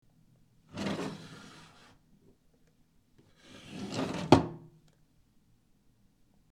На этой странице собраны уникальные звуки комода: скрипы ящиков, стук дерева, движение механизмов.
Деревянный ящик стола выдвигали и задвигали